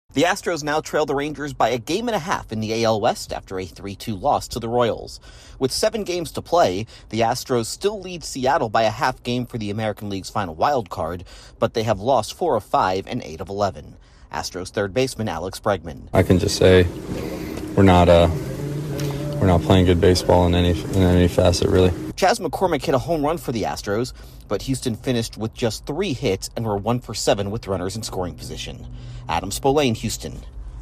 The Astros fall another game off the AL West lead. Correspondent